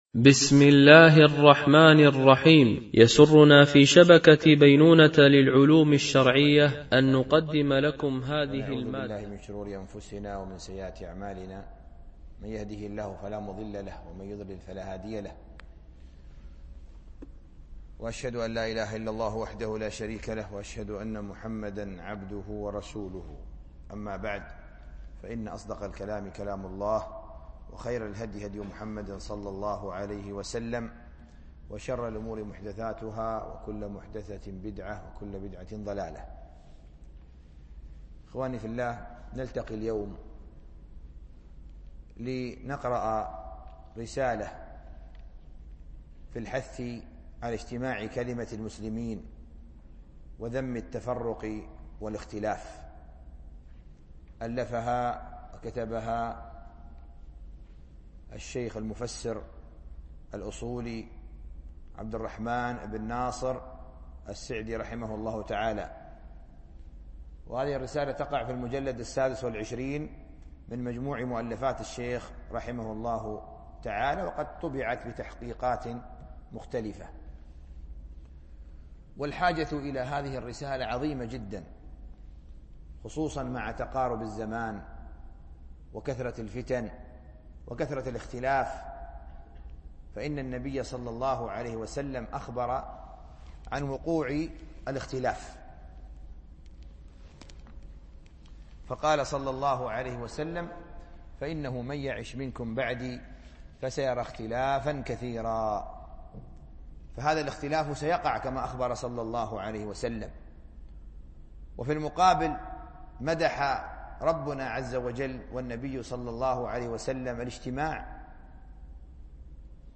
التعليق على رسالة في الحث على اجتماع كلمة المسلمين وذم التفرق والاختلاف ـ الدرس الأول
MP3 Mono 22kHz 32Kbps (CBR)